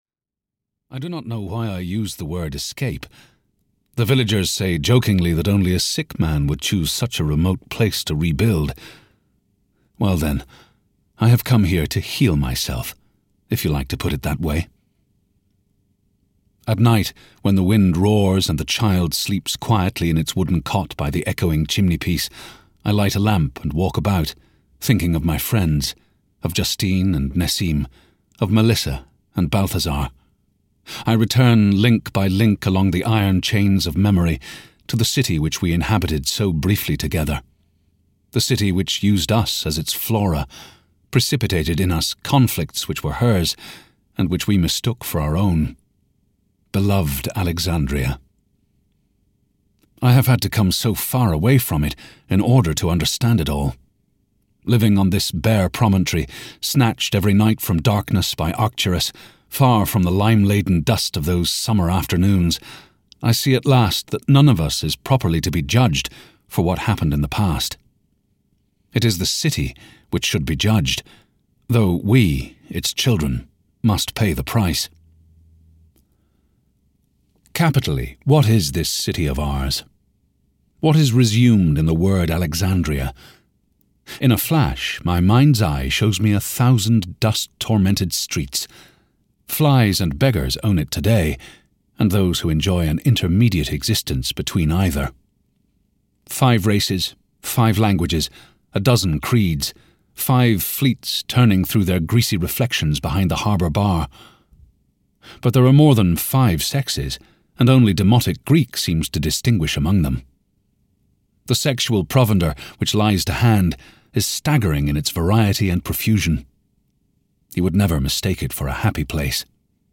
Justine (EN) audiokniha
Ukázka z knihy